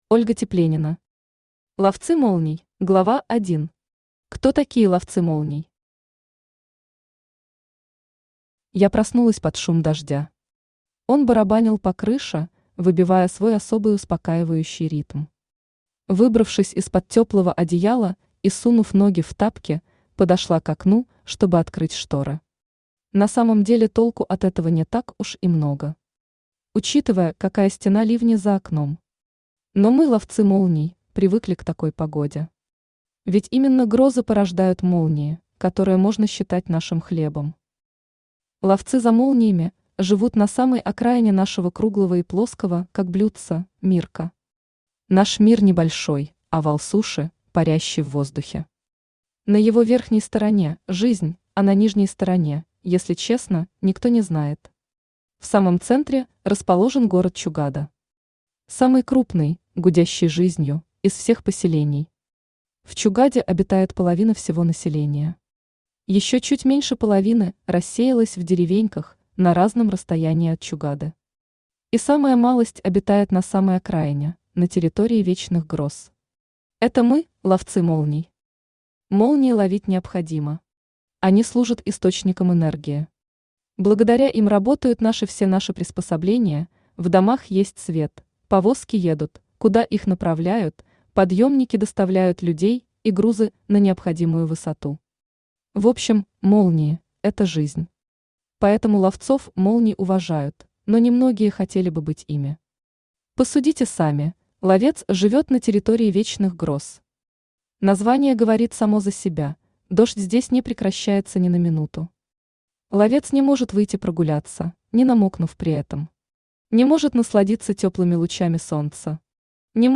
Aудиокнига Ловцы молний Автор Ольга Тепленина Читает аудиокнигу Авточтец ЛитРес.